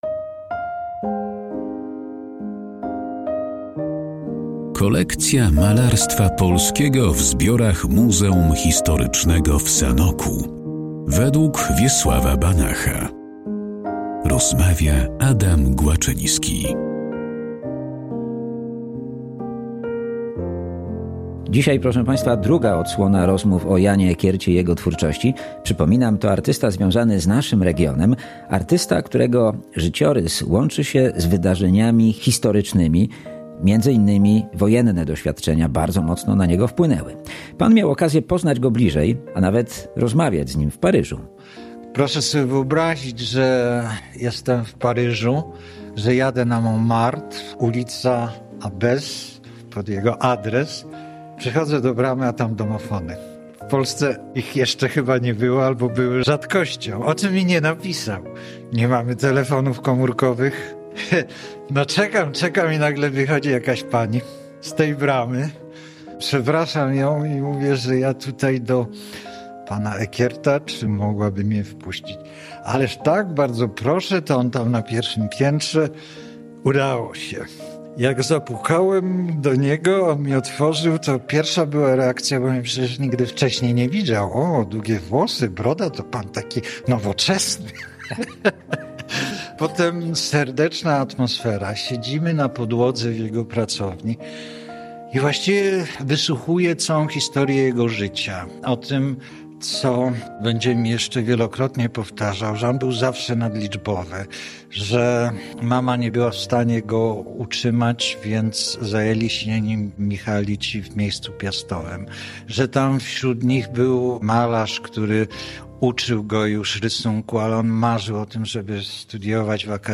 O jego dorobku i sanockiej kolekcji rozmawiają znawcy sztuki.